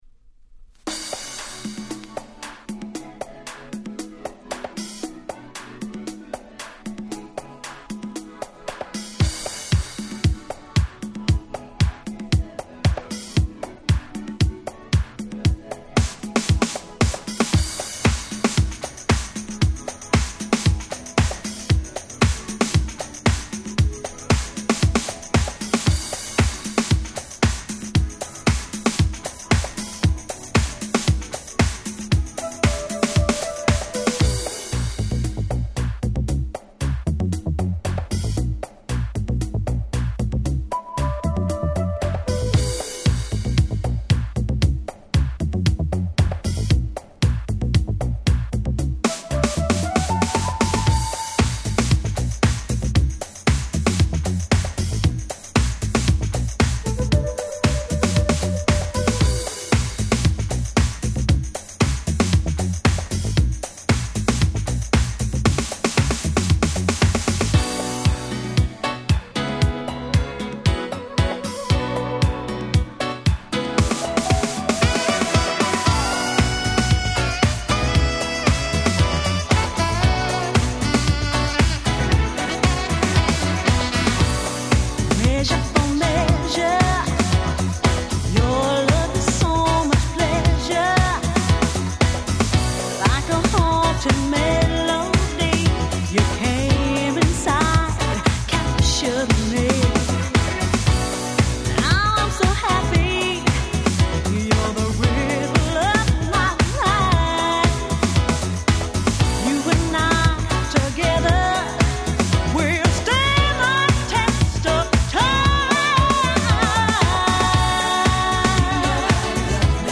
【Media】Vinyl 12'' Single
UK R&B Classic !!!!!